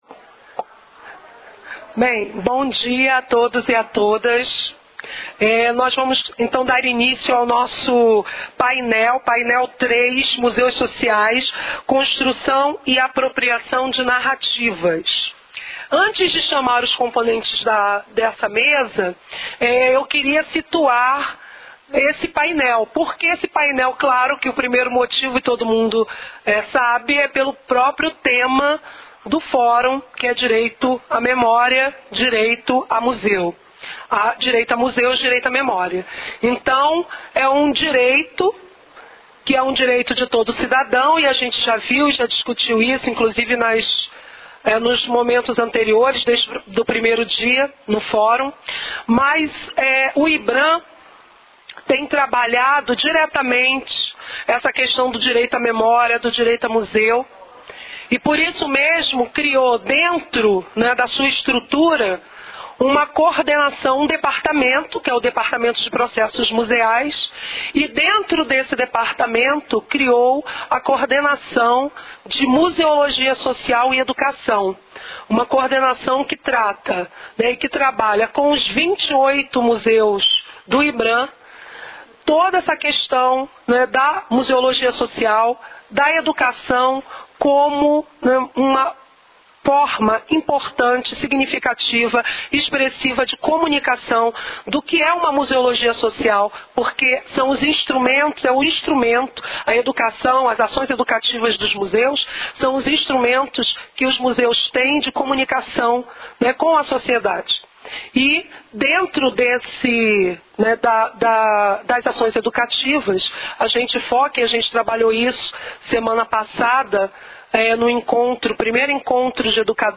Apresentações relativas ao tema Museus Sociais: Construção e Apropriação de Narrativas.